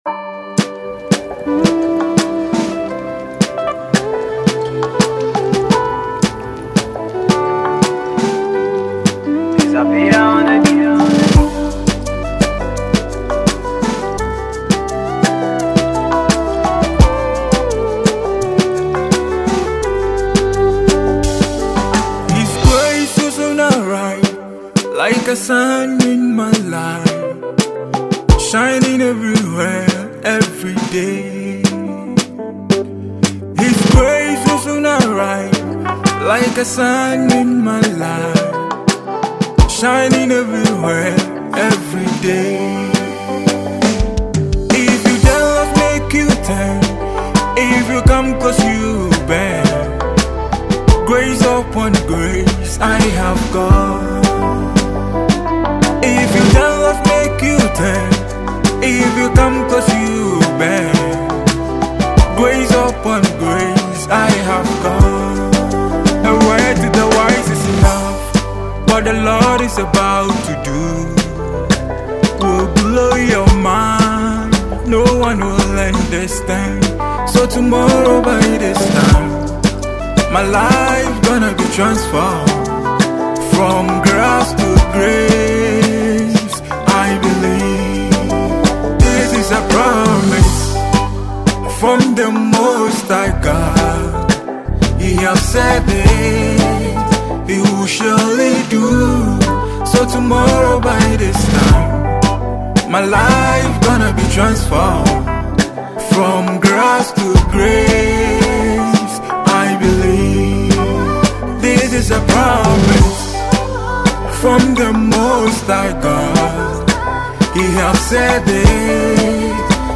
inspirational banger